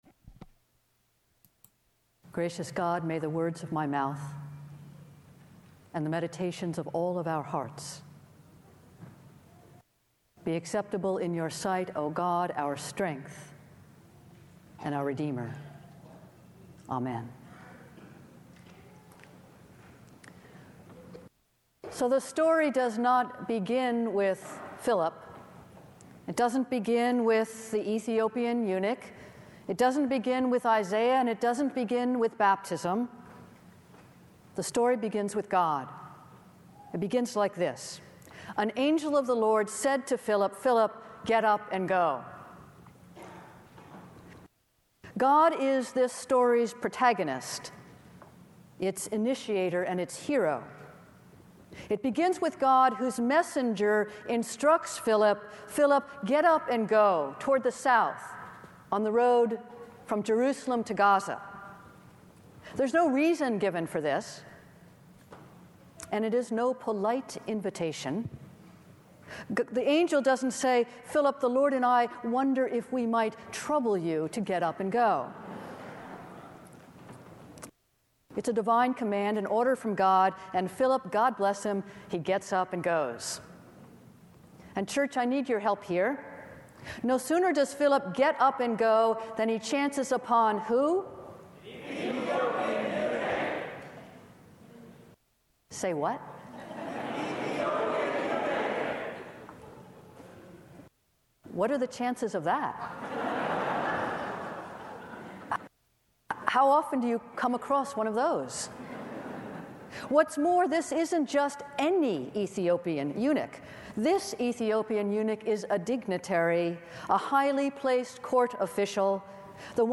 Founders' Day Service